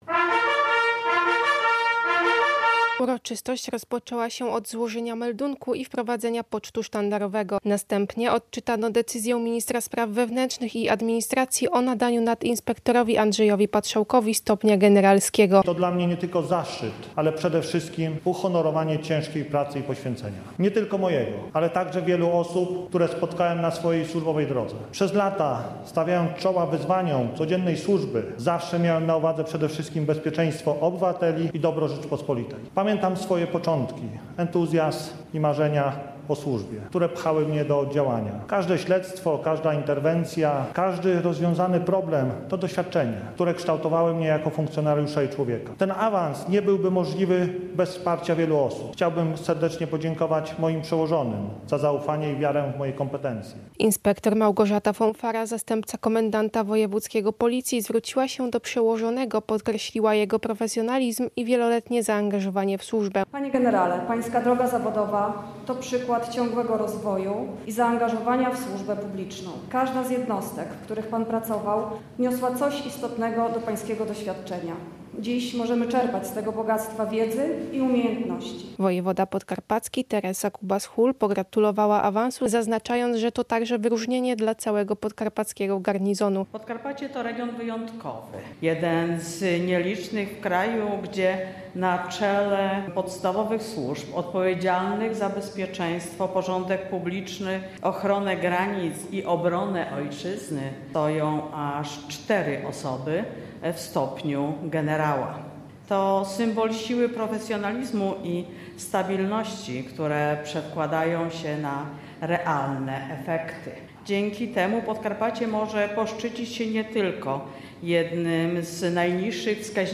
W swoim wystąpieniu nadinspektor Patrzałek podziękował za zaufanie i wsparcie, podkreślając, że nominacja to nie tylko zaszczyt, ale również zobowiązanie do jeszcze większego zaangażowania na rzecz bezpieczeństwa mieszkańców Podkarpacia.
Relacja